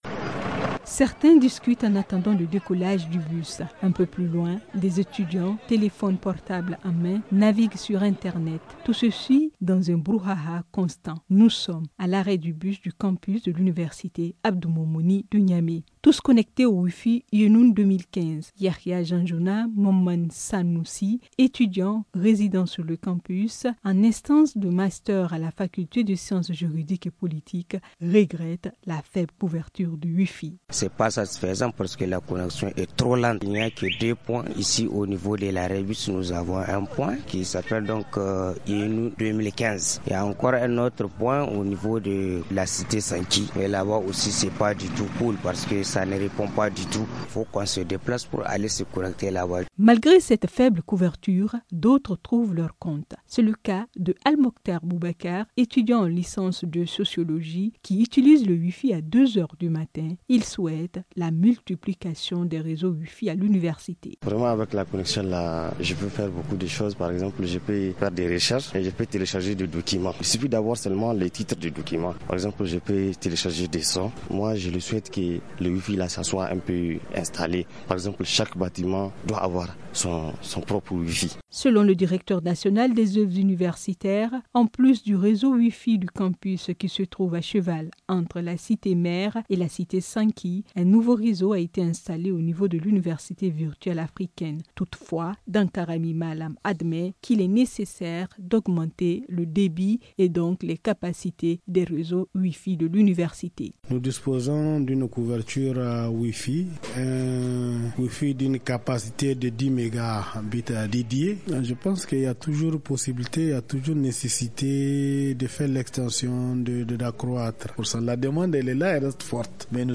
Reportage aujourd’hui à la cité Universitaire Abdou Moumouni de Niamey où la connexion wifi, c’est-à-dire de l’internet sans fil, est très prisée par les étudiants pour leurs recherches ou leurs loisirs.